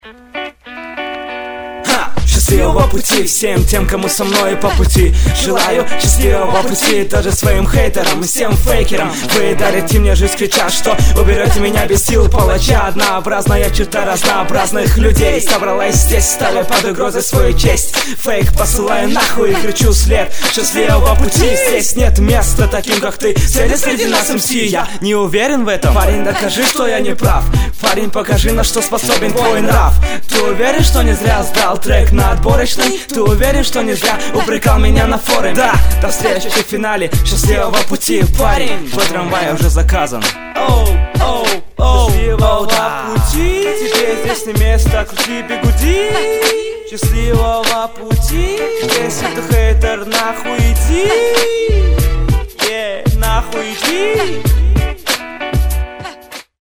P.S мой трек на отборочный раунд баттла хип-хоп.ру